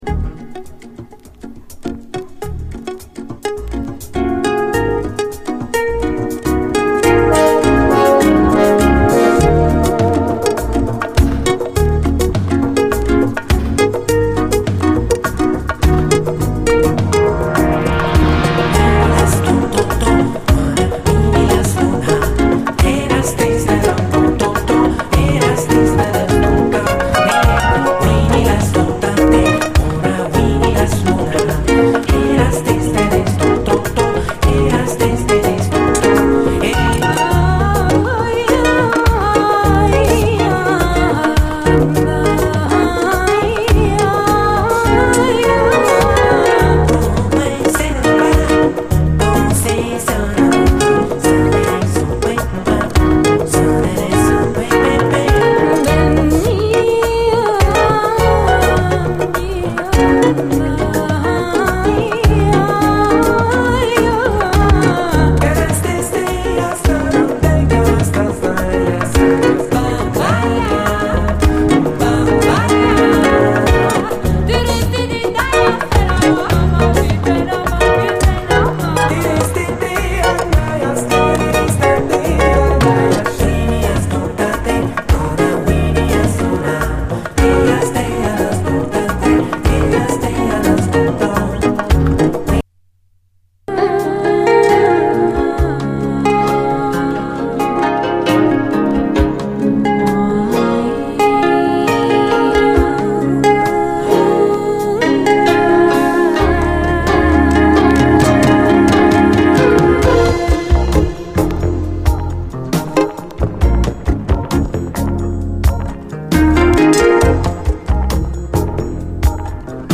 ファットなビートに加え女性コーラスのアレンジも素晴らしい
泣きの哀愁メロウ・ファンク
後半にドラム・ブレイクもあり。